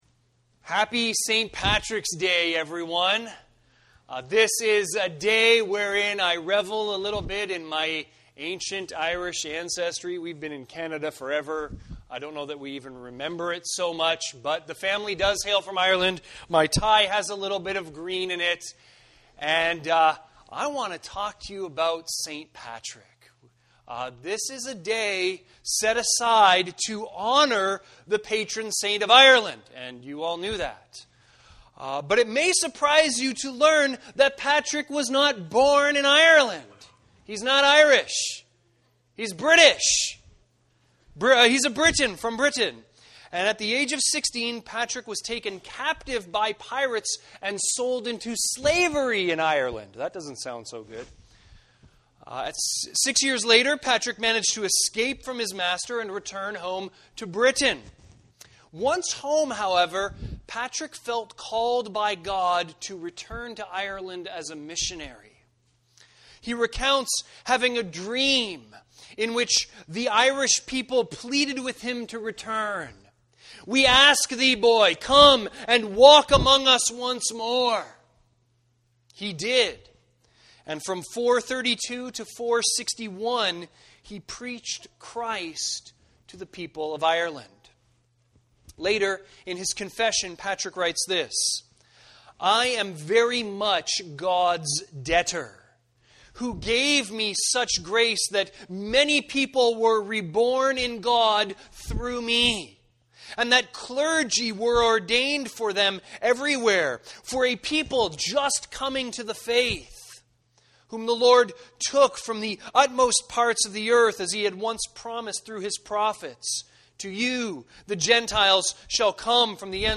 Sermons | Campbell Baptist Church